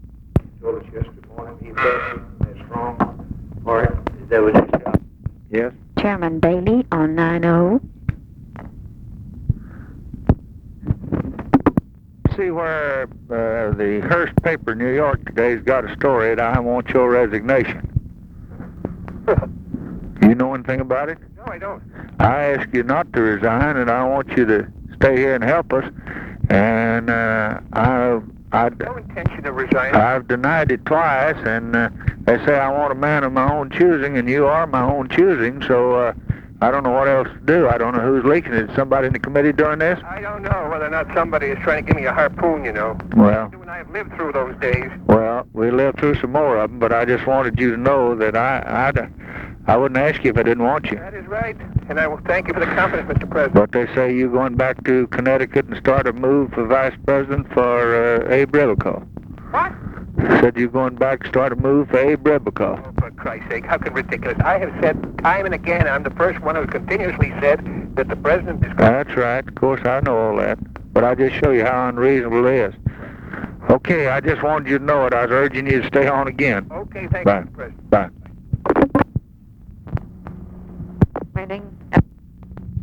Conversation with JOHN BAILEY, January 30, 1964
Secret White House Tapes